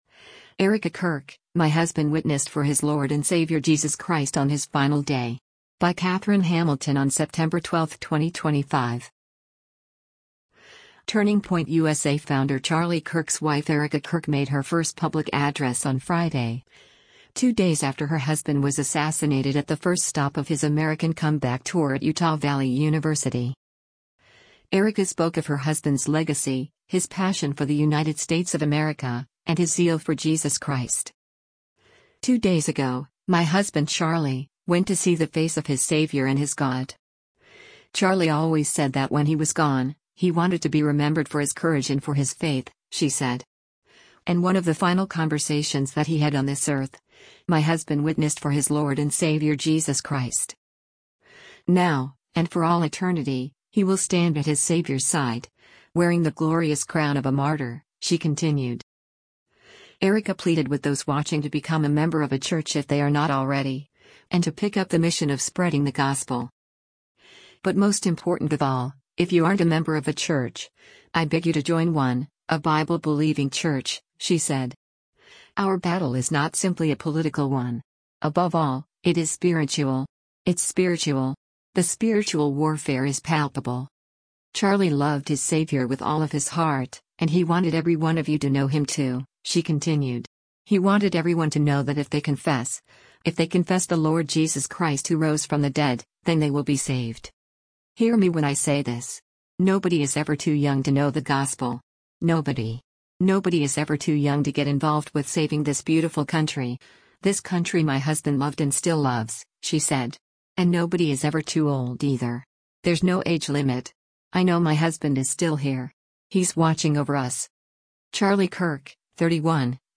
Turning Point USA founder Charlie Kirk’s wife Erika Kirk made her first public address on Friday, two days after her husband was assassinated at the first stop of his “American Comeback Tour” at Utah Valley University.